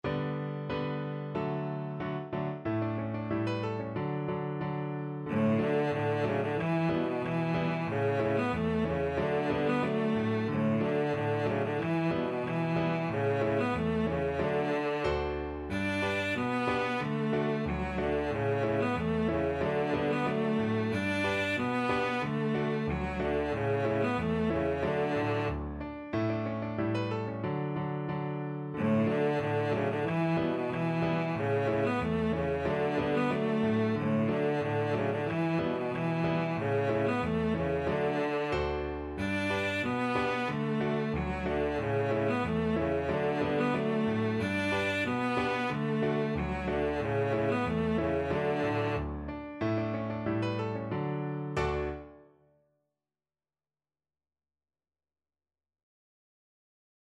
Cello version
2/2 (View more 2/2 Music)
Jolly =c.92
Classical (View more Classical Cello Music)